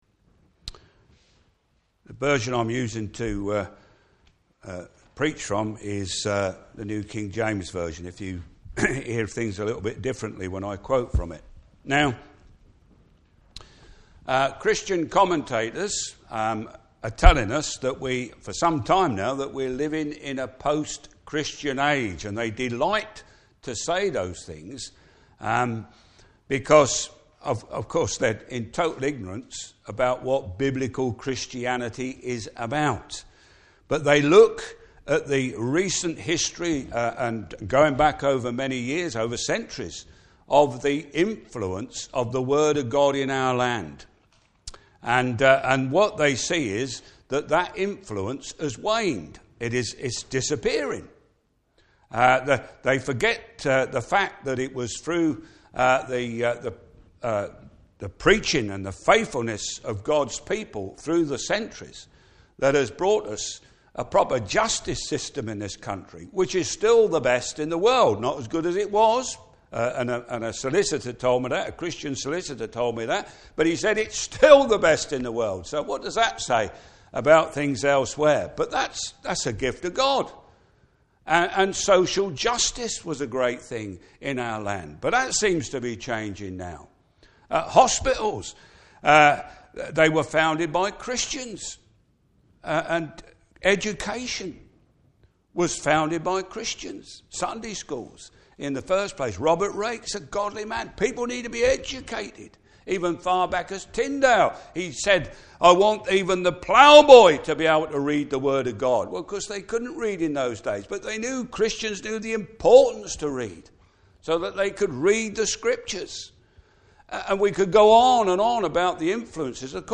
Service Type: Morning Service Christ's eternal kingdom.